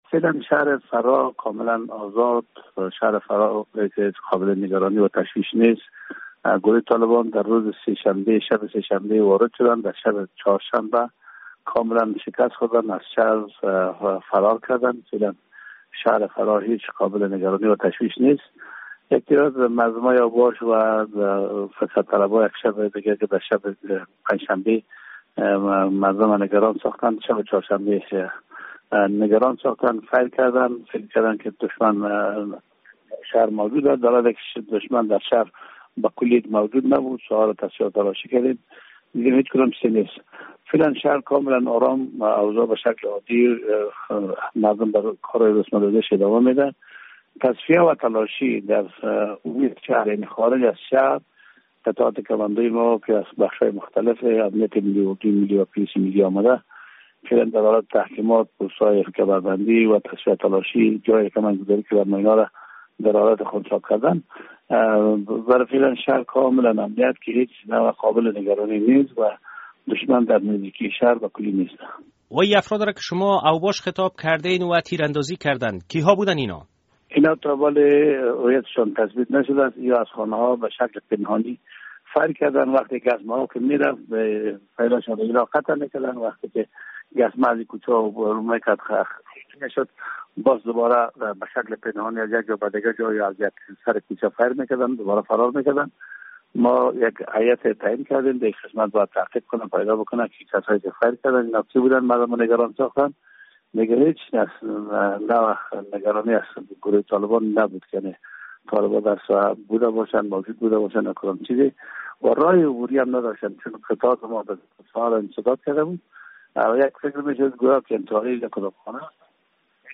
مصاحبه - صدا
عبدالبصیر سالنگی والی فراه